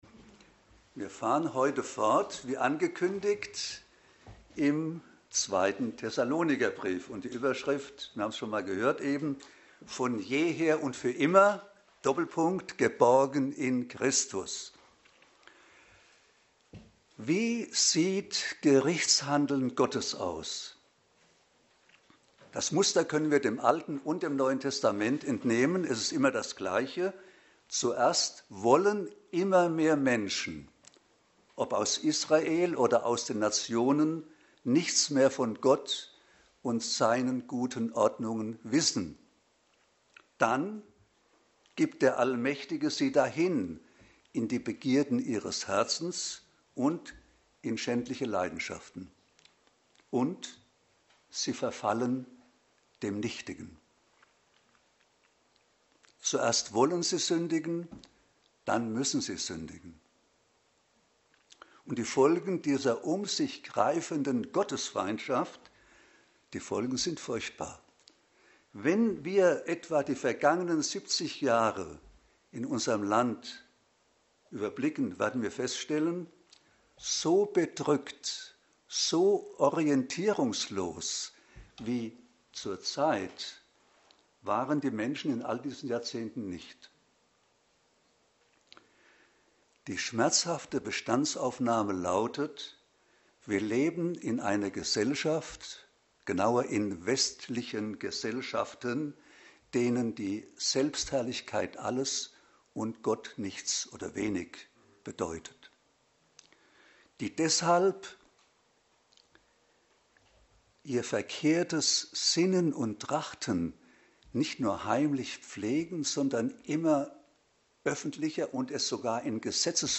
Psalm 30 Dienstart: Predigt Sonstige Die Herrlichkeit des Herrn 28.